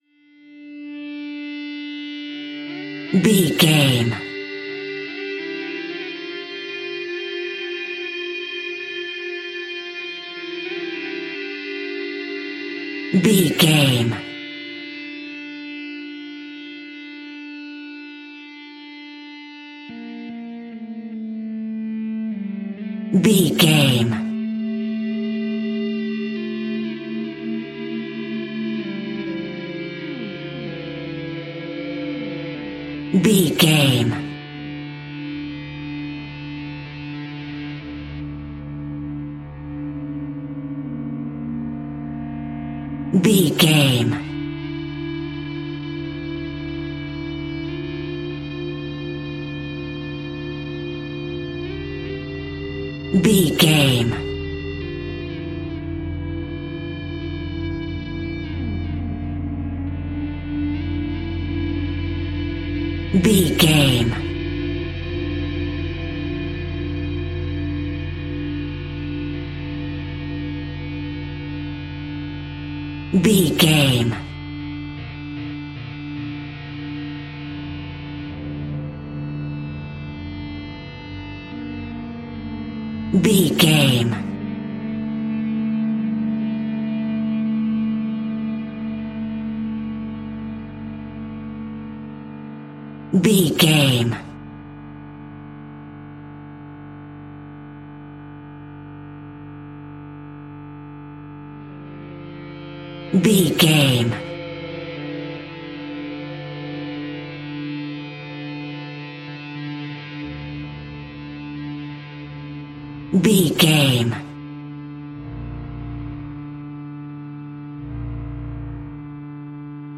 A Nightmare of Guitars.
Aeolian/Minor
D
ominous
dark
haunting
eerie
electric guitar
horror